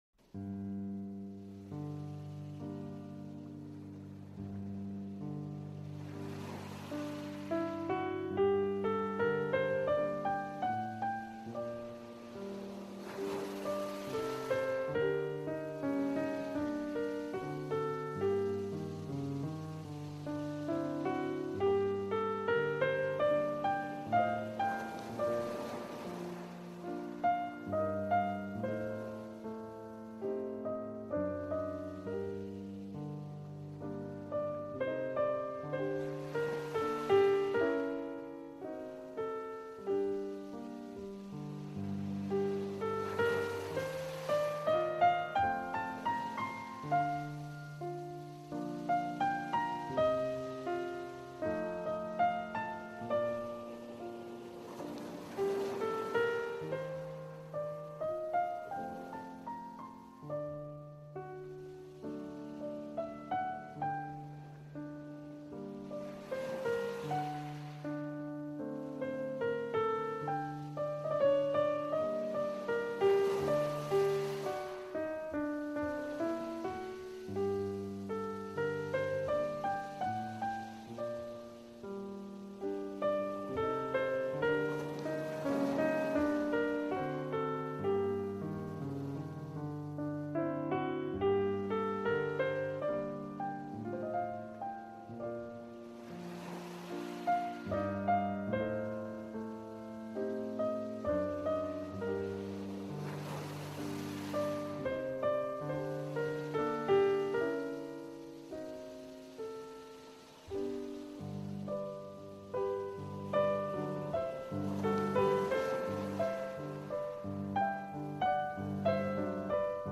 Chopin : Piano Pour Lecture et Étude